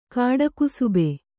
♪ kāḍakusube